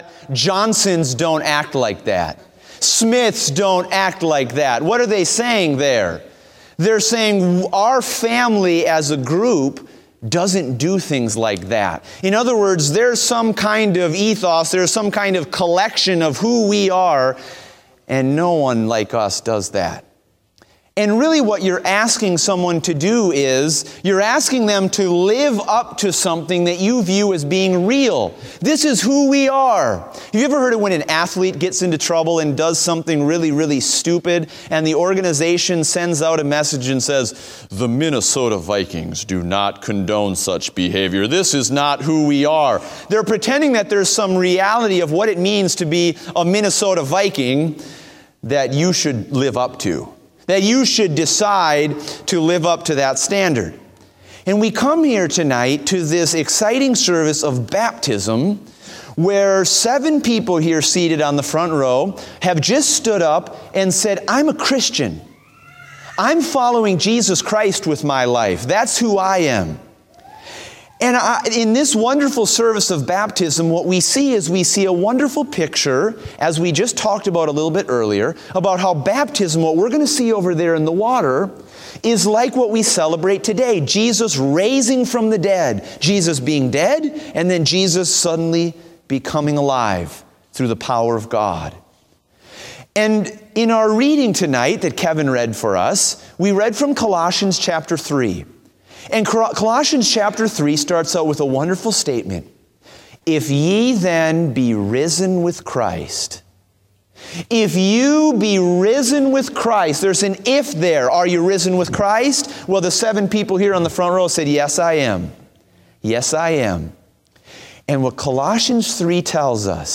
PM Easter Service